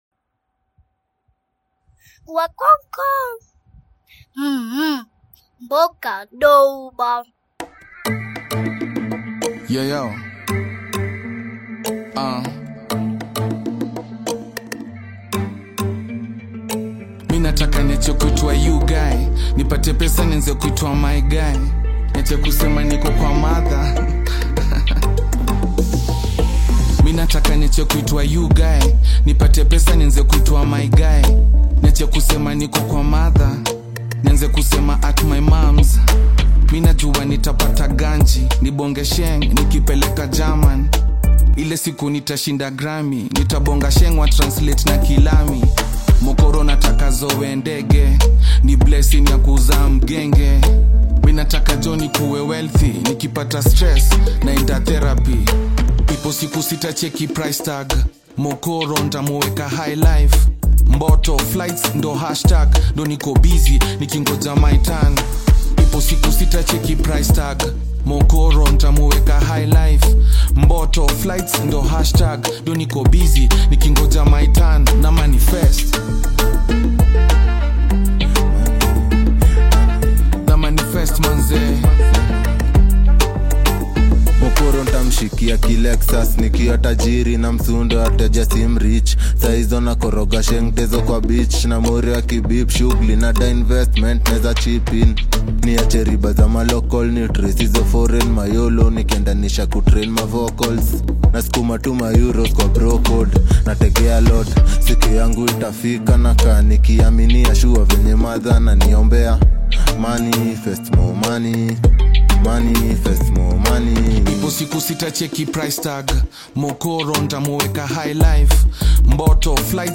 kenyan music